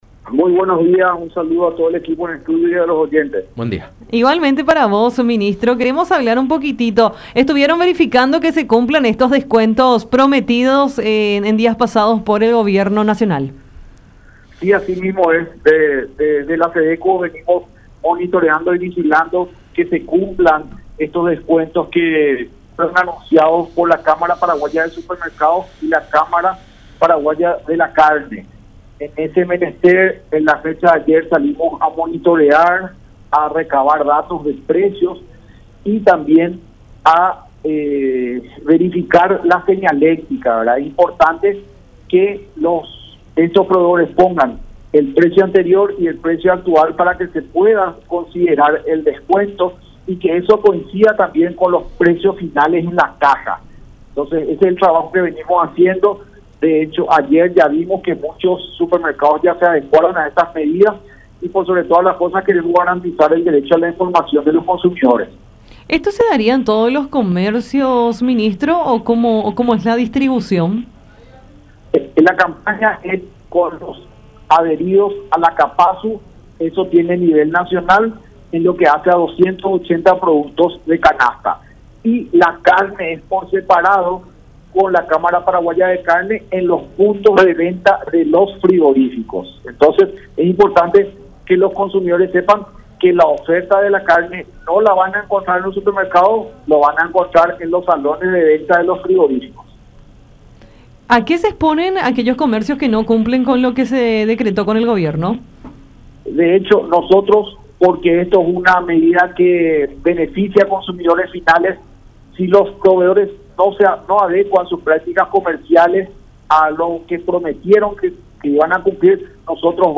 “Nosotros estamos haciendo los monitoreos y vigilando para que se cumplan con estos precios. Hemos solicitado a los municipios con los que tenemos convenios firmados para que nos ayuden a hacer estos trabajos”, dijo Juan Marcelo Estigarribia, titular de SEDECO, en conversación con Enfoque 800 por La Unión.